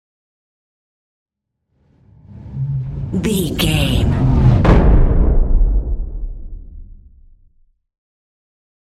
Cinematic whoosh to hit deep
Sound Effects
Atonal
dark
futuristic
intense
tension